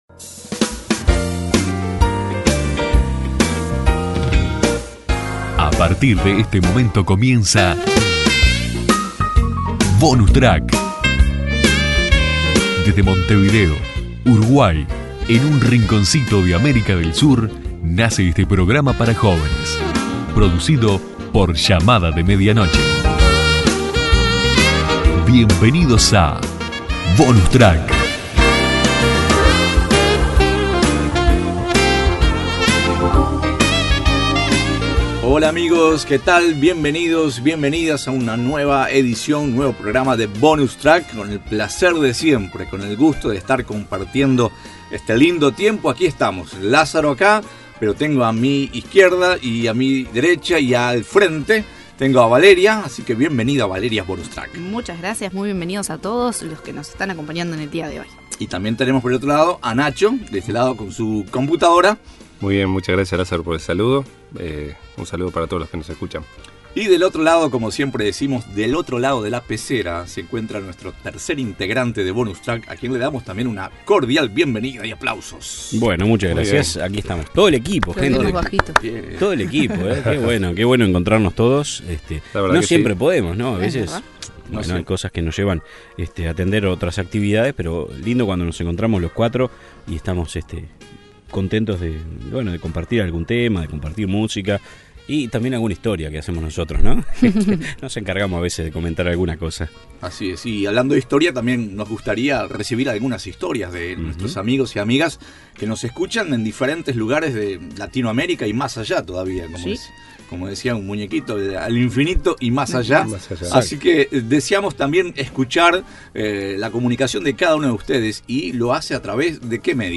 Bonus Track, te invita a que te prendas en sintonía durante 28 minutos para compartir un poco de todo: opiniones, invitados, un buen tema de conversación y la buena música, que no debe faltar.